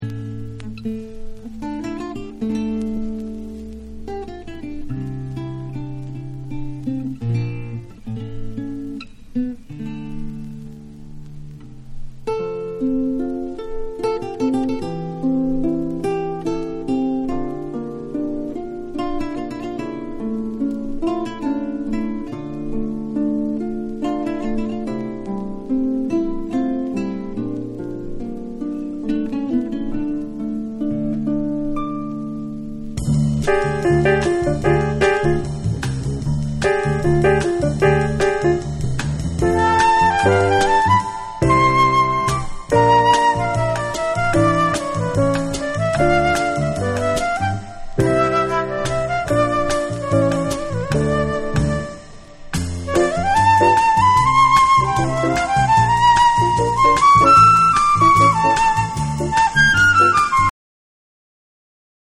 Well, pre-classical European music if you must know.